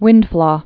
(wĭndflô)